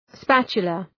Προφορά
{‘spætʃələ}